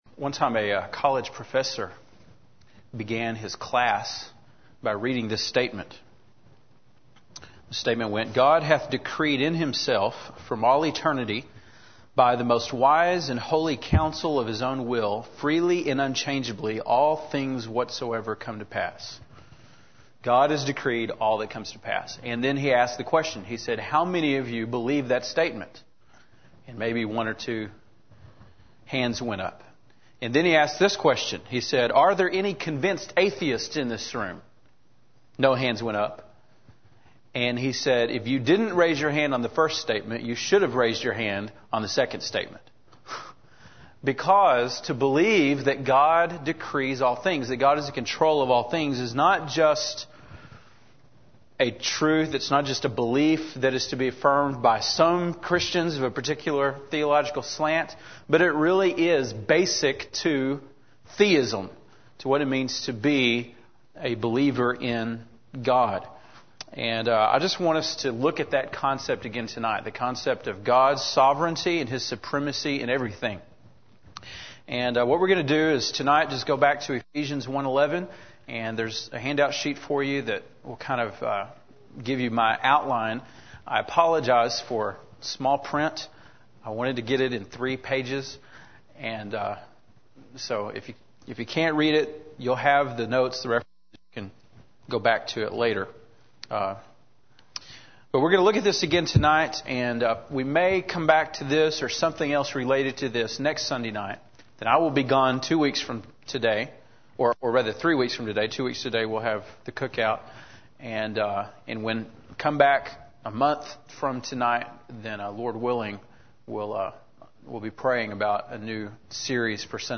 September 5, 2004 (Sunday Evening)